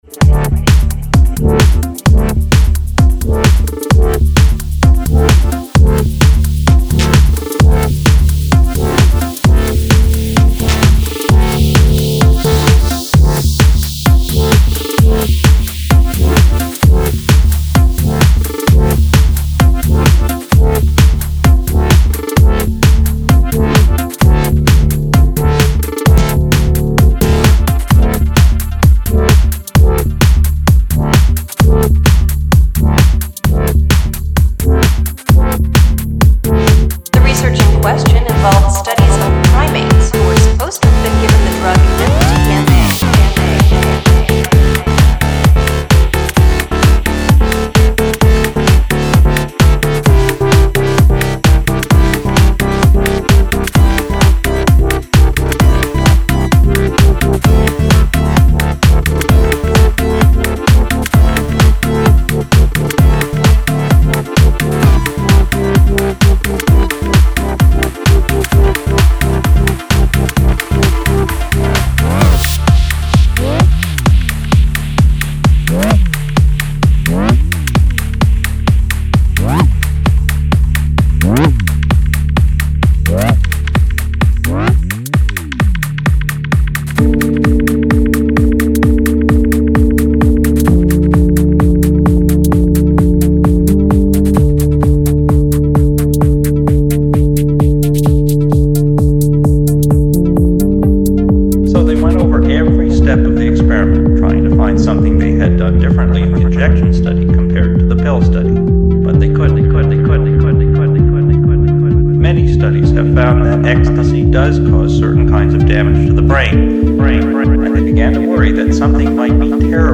Genres Techno Tech House